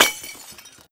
terraria_pot.wav